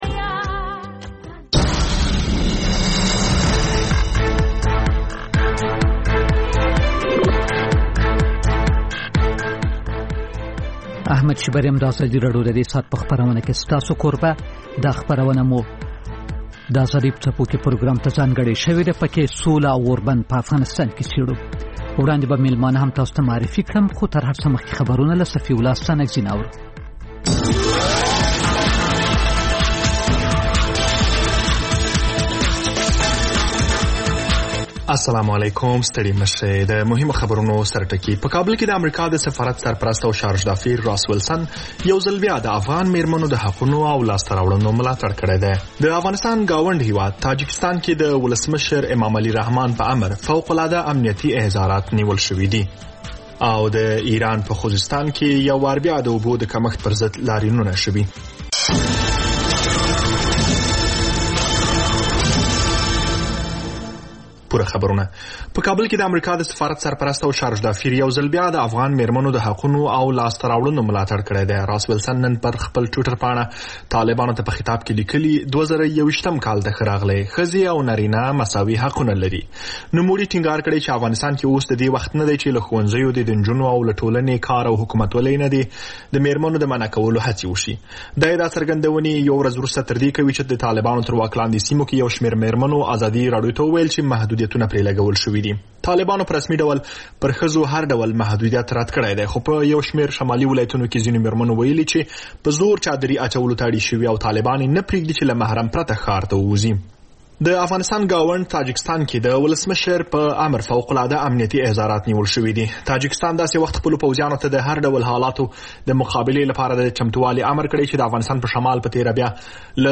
خبرونه